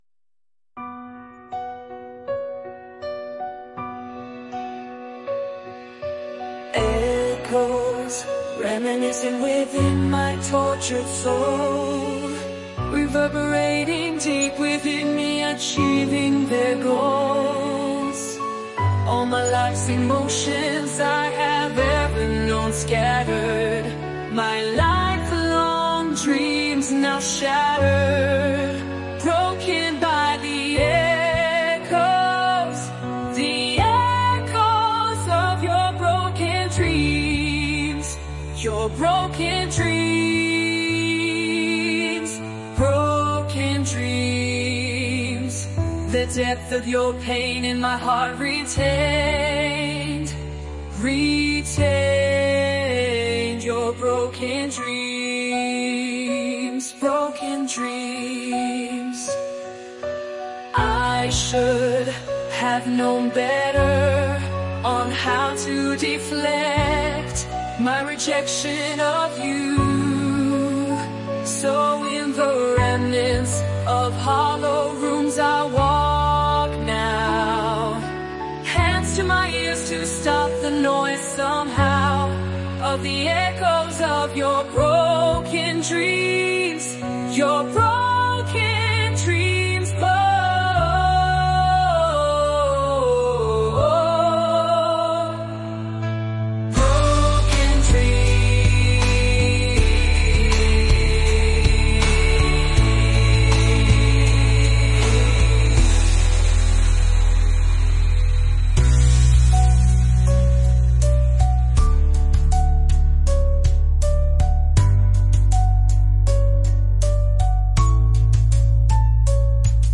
No, I wish, the music and vocals are AI, but as you know the words are 100% all mine . . .